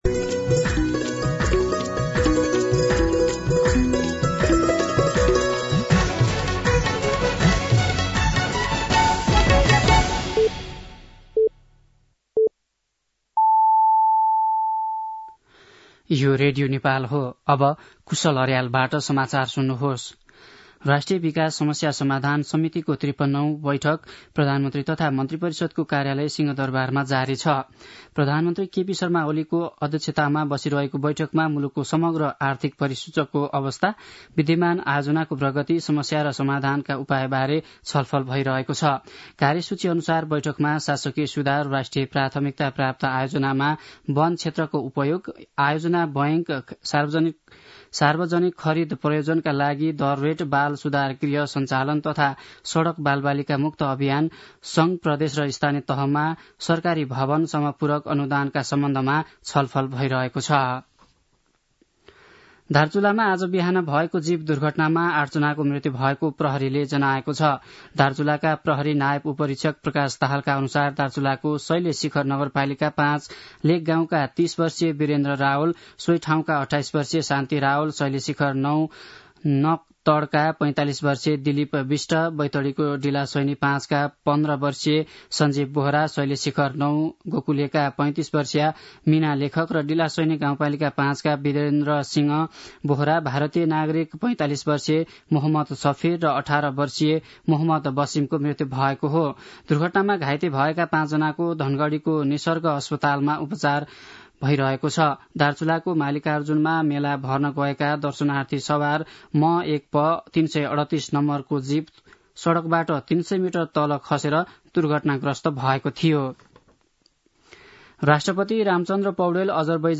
दिउँसो ४ बजेको नेपाली समाचार : १ मंसिर , २०८१
4-pm-Nepali-News-1.mp3